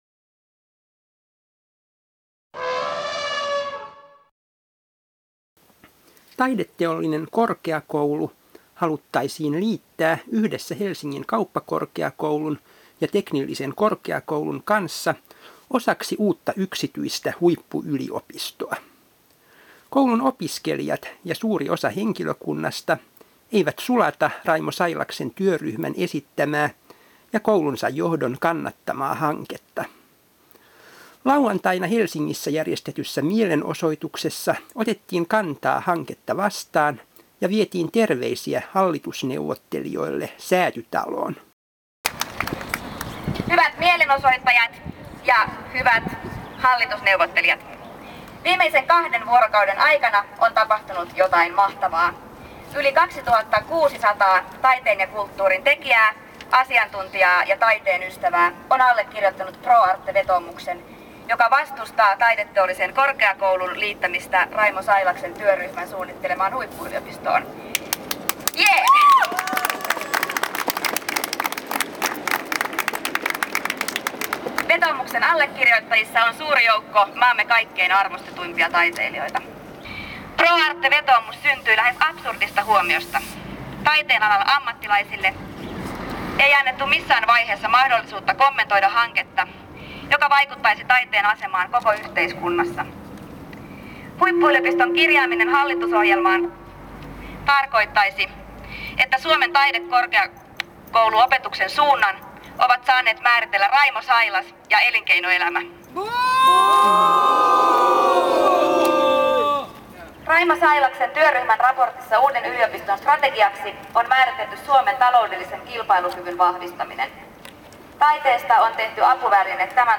Huippuyliopisto, mielenosoitus 15.4.2007 KULTTUURIVIHKOT
Valtaosa Taideteollisen korkeakoulun opiskelijoista ja henkilökunnasta ei kannata TaiKin liittymistä suunnitteilla olevaan teknis-kaupalliseen huippuyliopistoon. Helsingissä järjestetyssä mielenosoituksessa korostettiin taideopetuksen riippumattomuuden turvaamista.